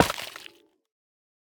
Minecraft Version Minecraft Version 1.21.5 Latest Release | Latest Snapshot 1.21.5 / assets / minecraft / sounds / block / sculk_catalyst / break7.ogg Compare With Compare With Latest Release | Latest Snapshot
break7.ogg